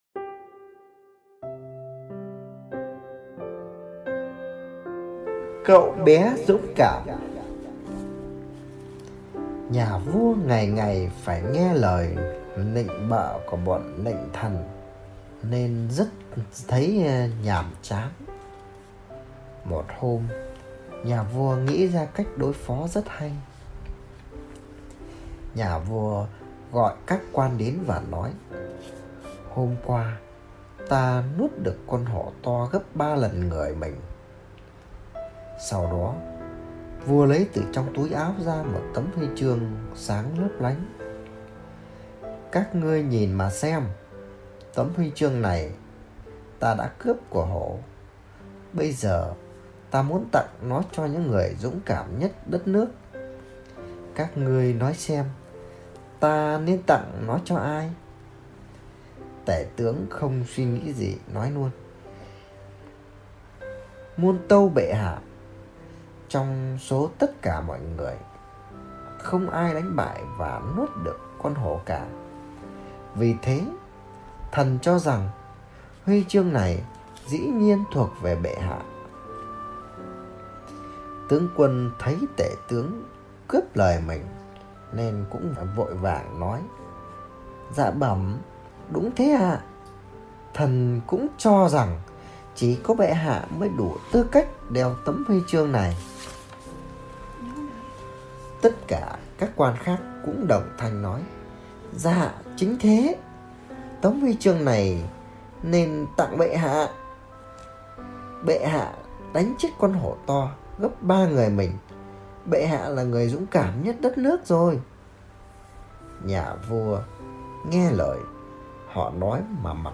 Sách nói | Cậu bé dũng cảm